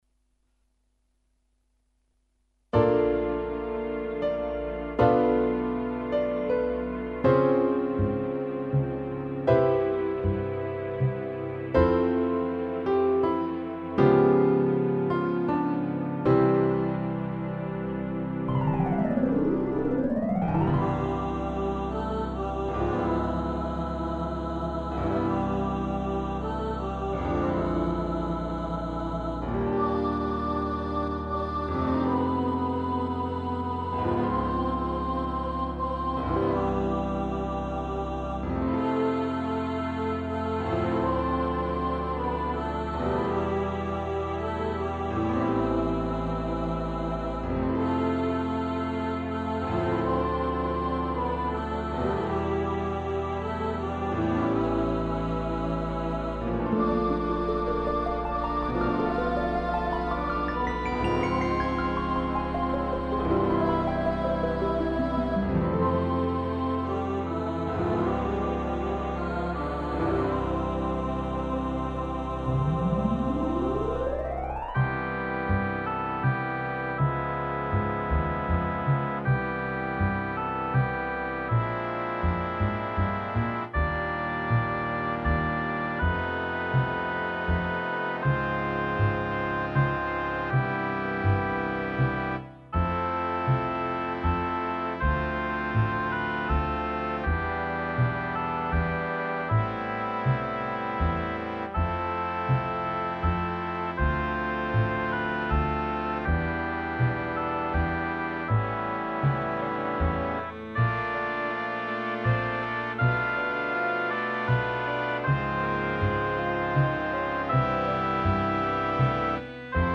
I have been very free with the harmony and chord progressions.
Choir   Piano   Harp     Strings     Bass    Drums    Tubular Bells
Oboe   4 Trombones    5 Saxophones